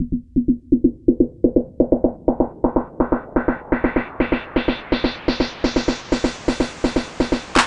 Index of /90_sSampleCDs/Classic_Chicago_House/FX Loops